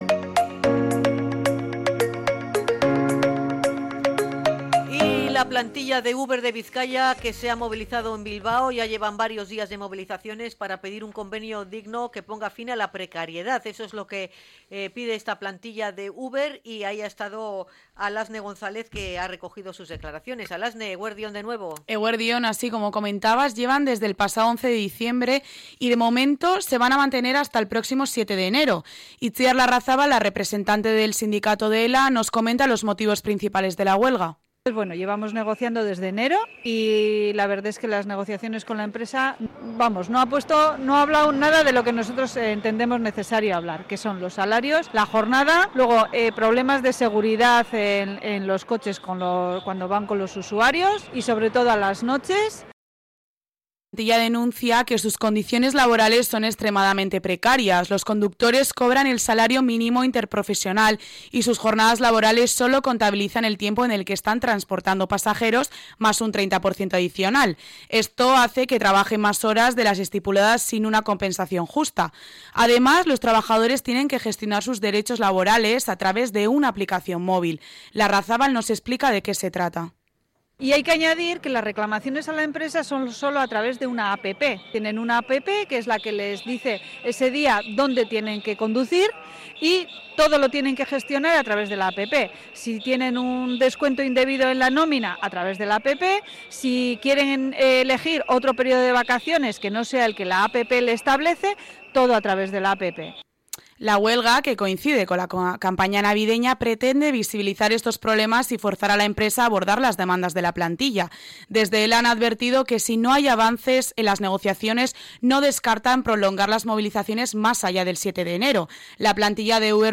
Crónica huelga Uber Bizkaia